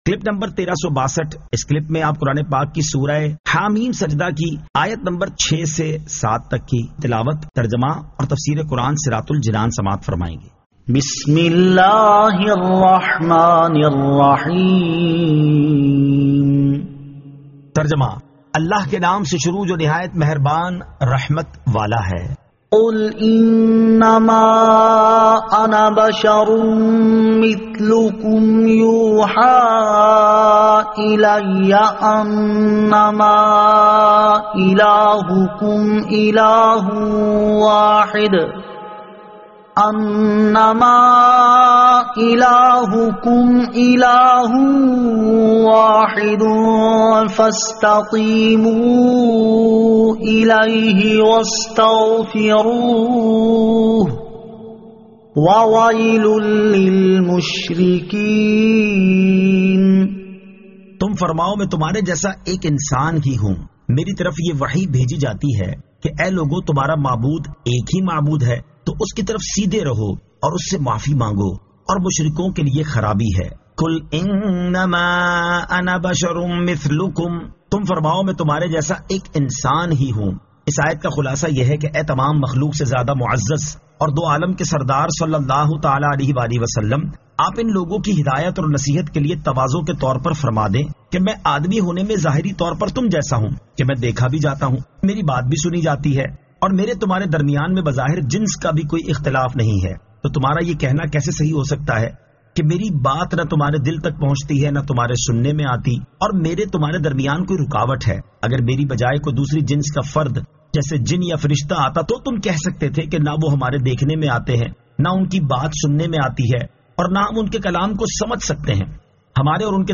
Surah Ha-Meem As-Sajdah 06 To 07 Tilawat , Tarjama , Tafseer